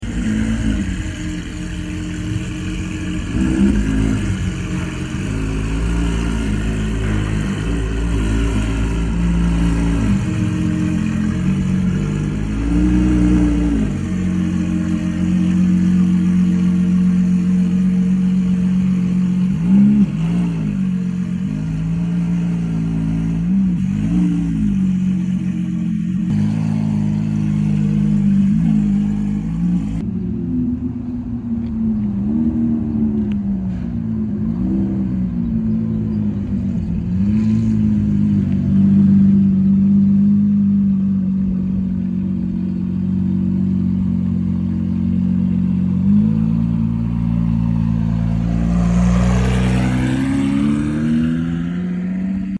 Check out this gorgeous Lamborghini sound effects free download
Check out this gorgeous Lamborghini Aventador Ultimae Roadster finished in “Arancio Xanto” with ANRKY Wheels AN20 AERO, Novitec springs and a Novitec Exhaust!